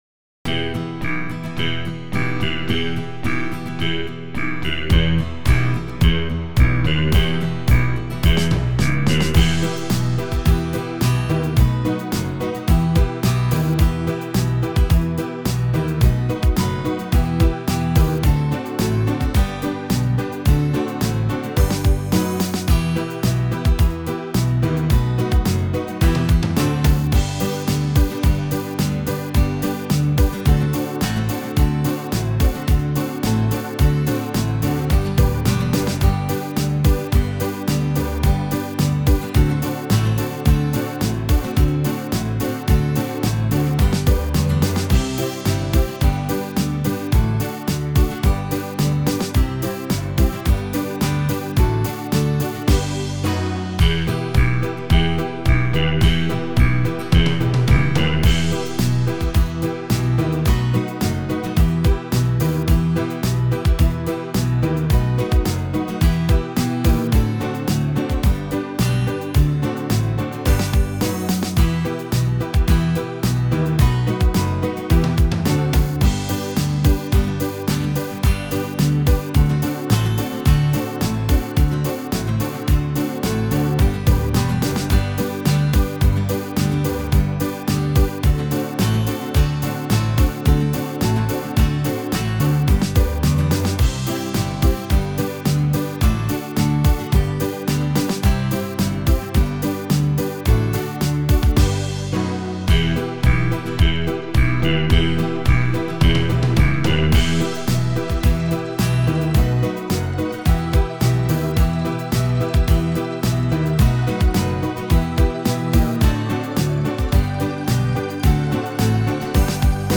A híressé vált cseh politikai dal karaoke változata.